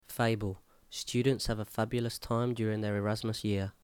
10 Fable  ˈfeɪbəl